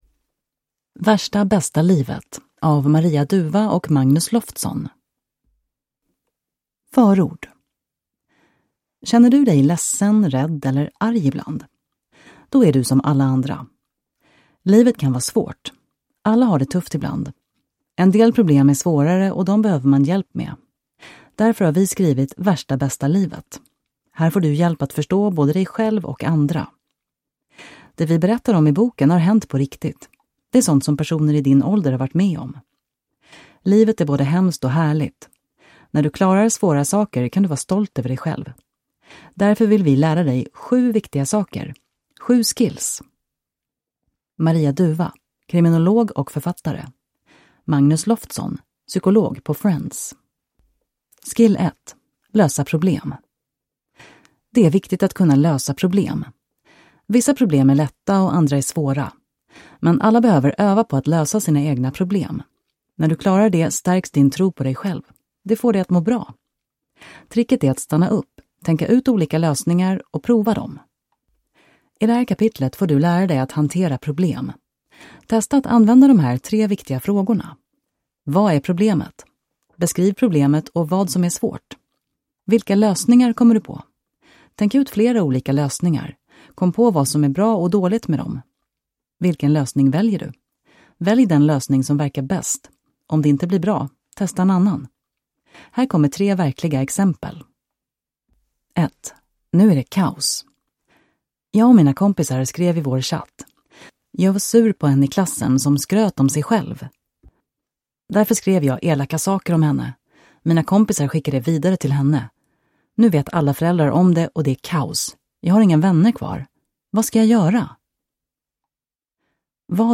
Värsta bästa livet (lättläst) (ljudbok) av Maria Dufva